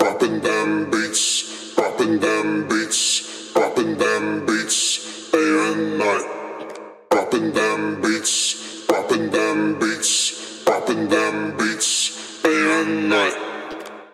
土地套件陷阱声乐
标签： 140 bpm Trap Loops Vocal Loops 2.38 MB wav Key : Unknown
声道立体声